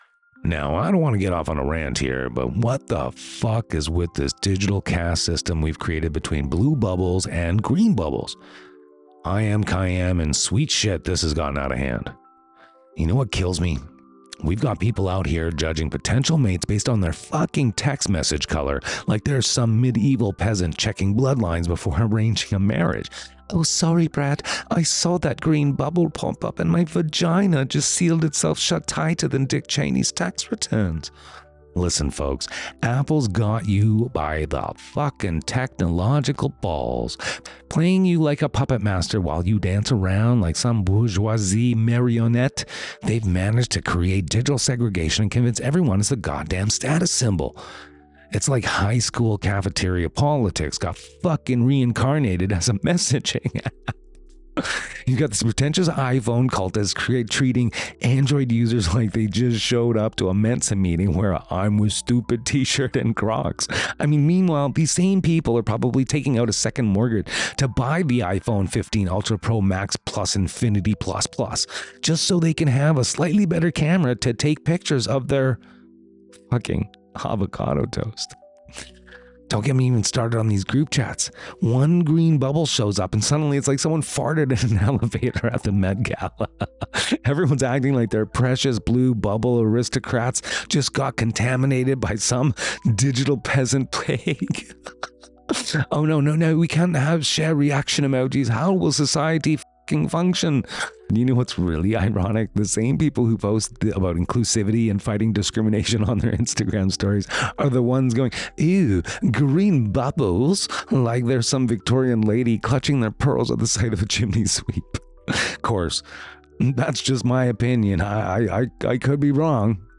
The F-bombs aren't just for shock—they're linguistic IEDs in our collective tech delusion.
001-RANT.mp3